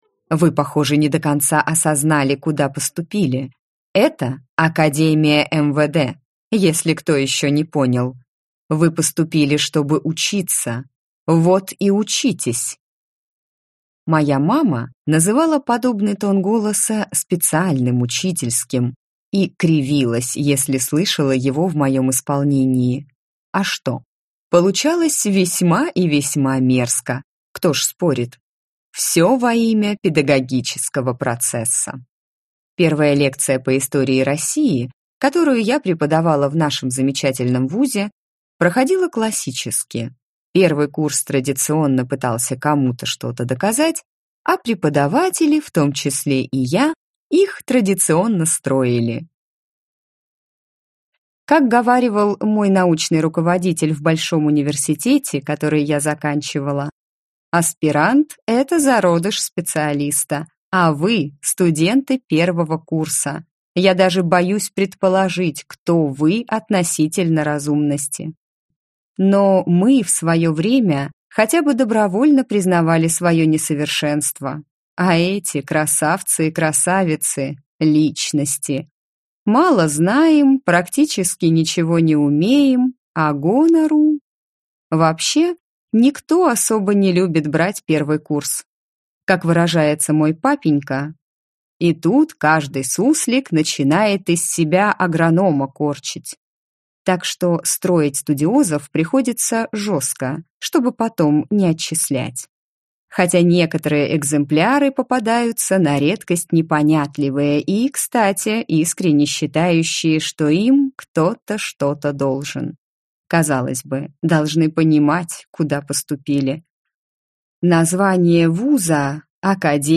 Аудиокнига Империя Тигвердов. Невеста для бастарда | Библиотека аудиокниг